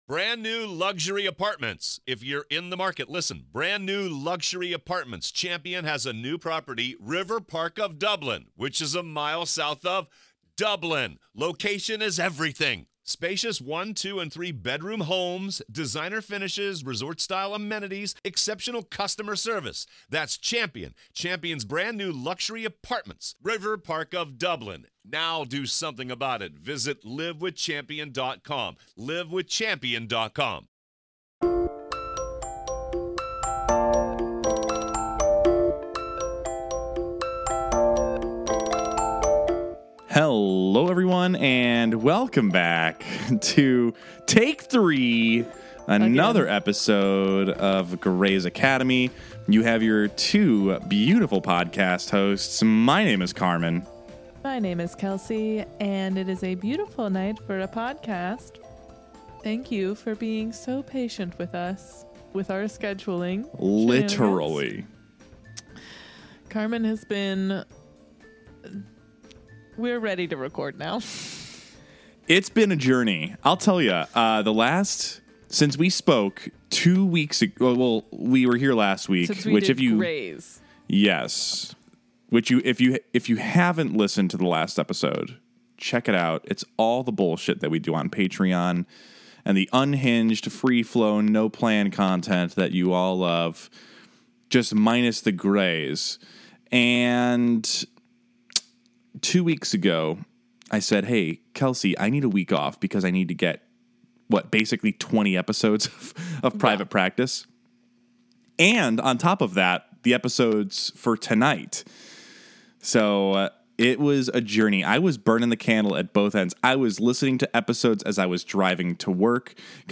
**This episode starts with some technical difficulties but is resolved in the first 5 minutes. We strive for high quality recordings and apologize for any impact on the listening experience.*